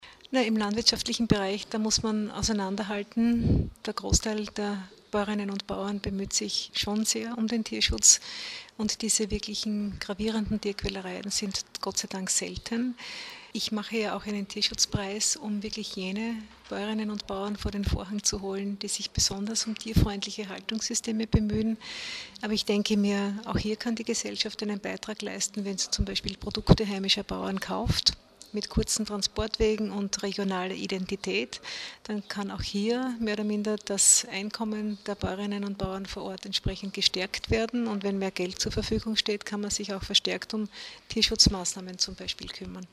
Graz (5. Juni 2013).- Gemeinsam mit dem zuständigen Landesrat Gerhard Kurzmann präsentierte die steirische Tierschutzombudsfrau Barbara Fiala-Köck heute Vormittag (05.06.2013) im Medienzentrum Steiermark ihren aktuellen Tätigkeitsbericht.